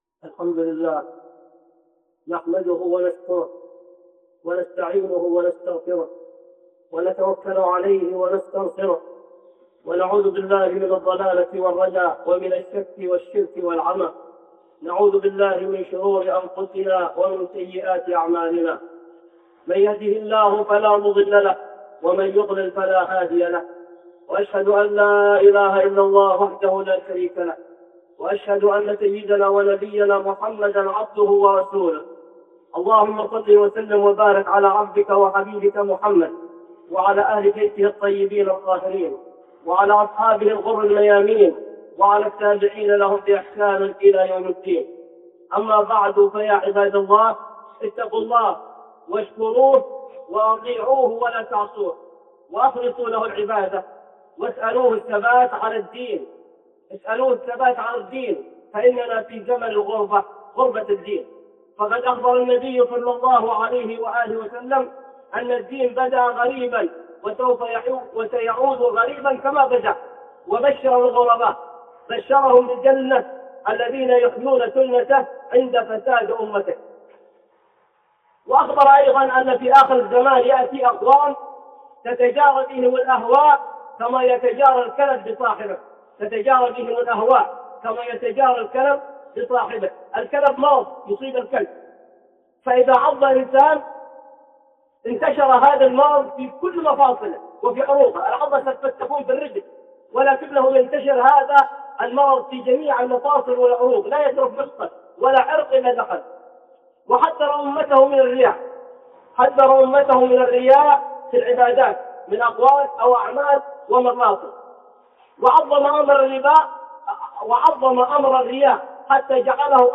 (خطبة جمعة) خطورة الرياء